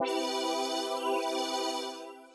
05_PostApoc.wav